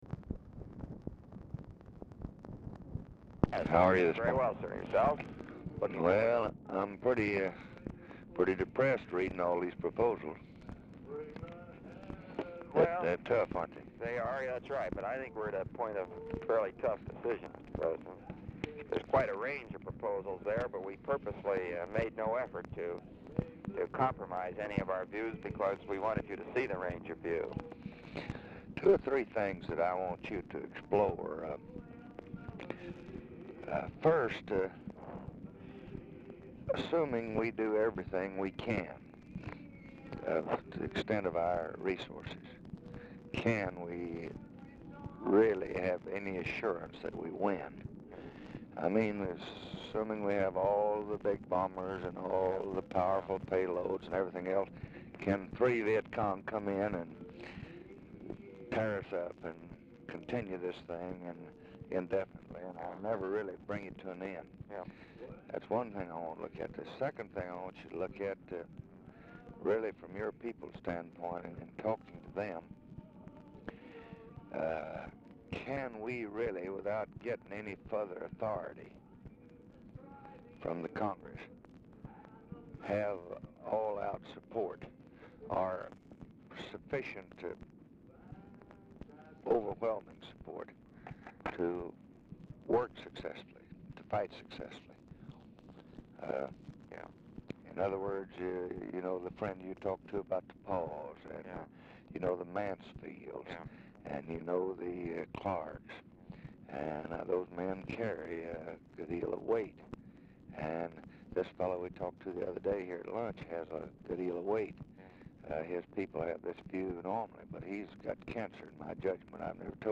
Telephone conversation # 8302, sound recording, LBJ and ROBERT MCNAMARA, 7/2/1965, 8:41AM
"FROM BEDROOM" WRITTEN ON DICTABELT
MUSIC AUDIBLE IN BACKGROUND